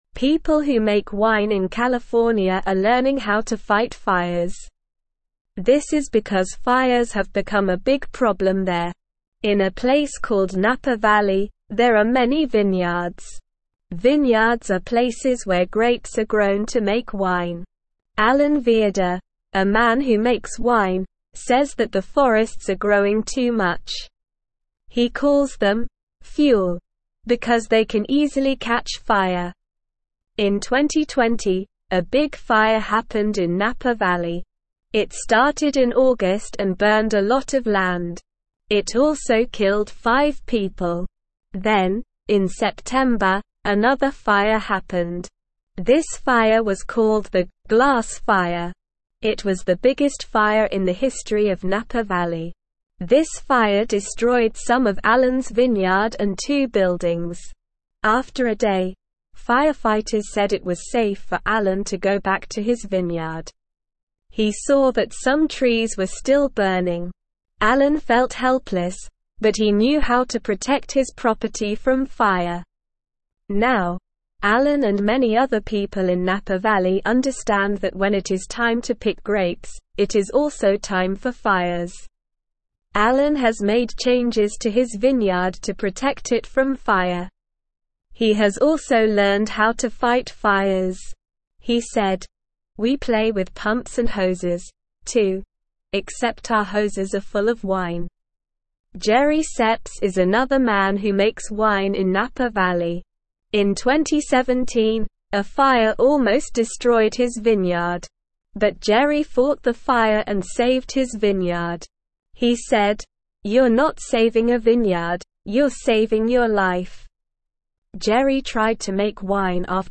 Slow
English-Newsroom-Beginner-SLOW-Reading-California-Winemakers-Learn-to-Fight-Fires.mp3